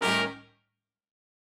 GS_HornStab-A7b2b5.wav